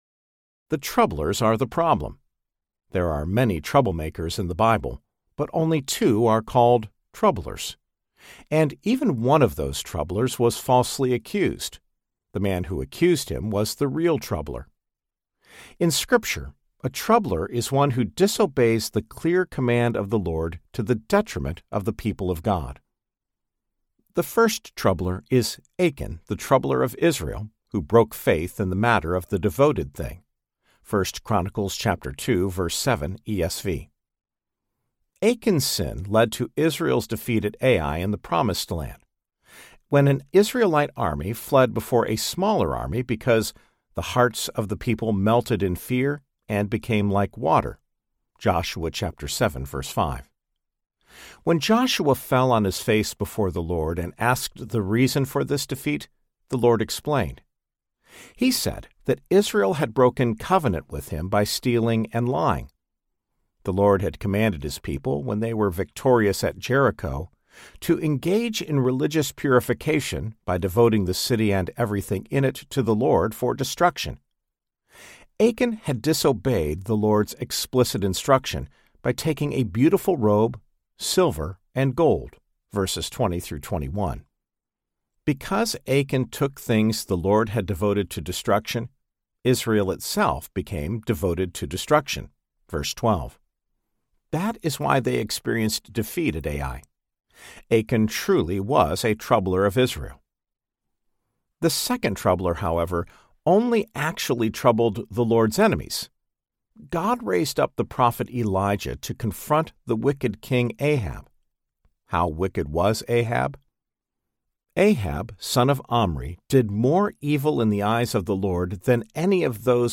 The Assurance of Salvation Audiobook
Narrator
6.8 Hrs. – Unabridged